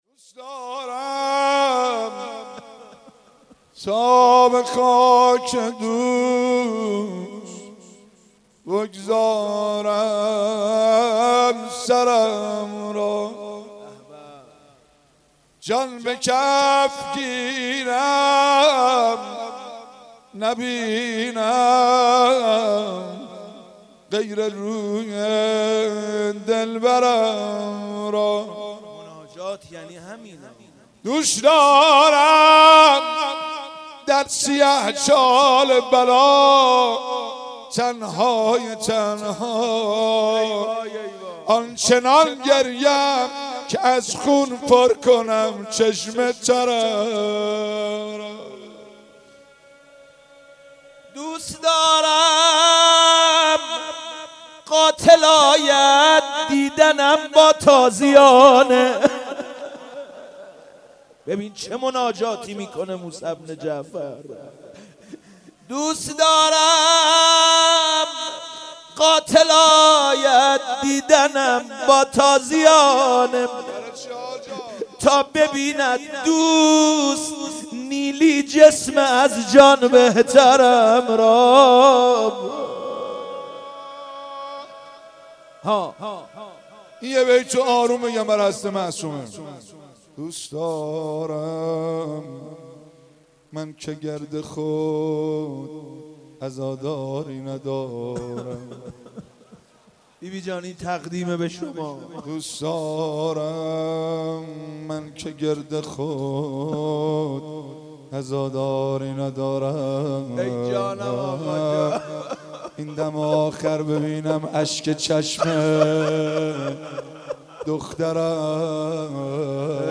مناجات
روضه امام کاظم علیه السلام دریافت